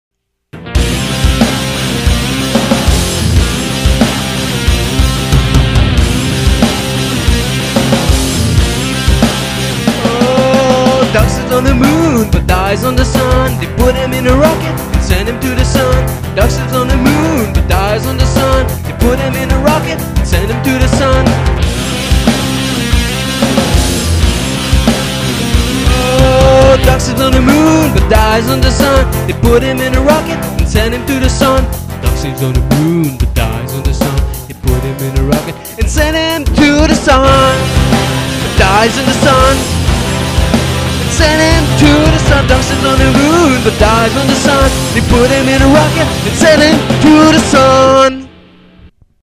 Rock Cover